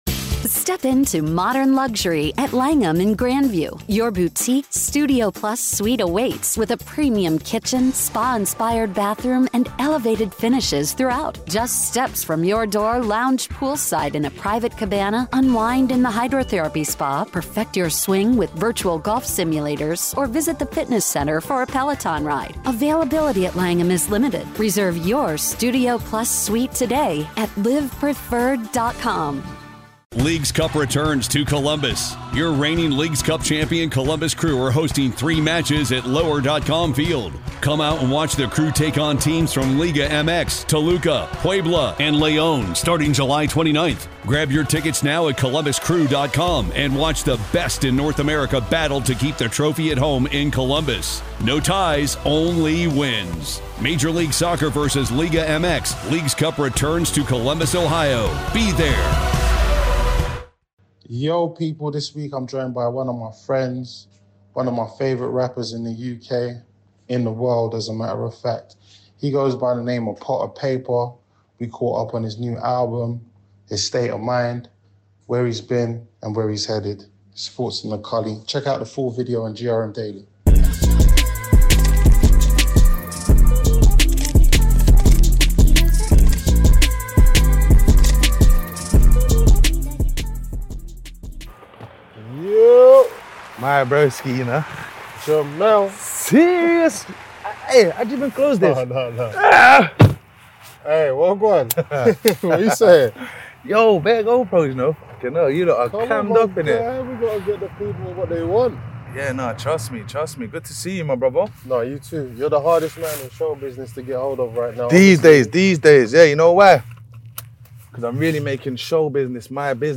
The latest episode of Thoughts In A Culli brings a riveting conversation with none other than Potter Payper, the acclaimed rapper.